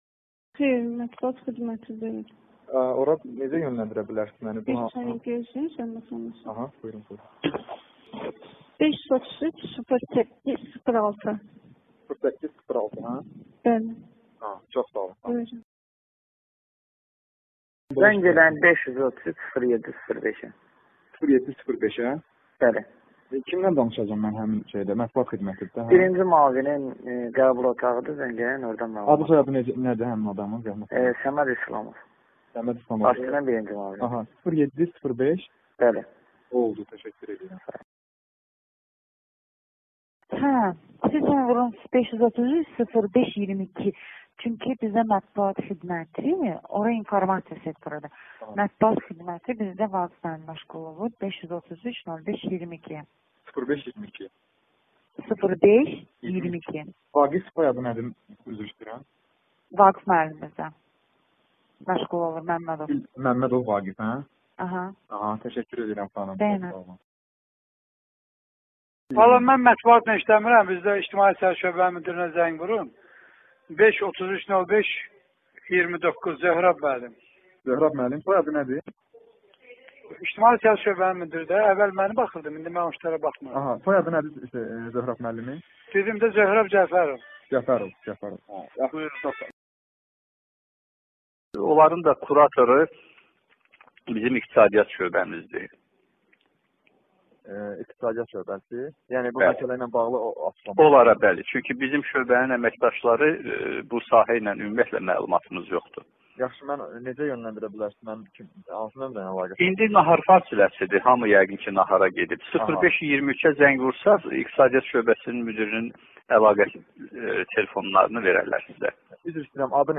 Jurnalist telefonda cəmi bir suala cavab tapmaq istəyirdi.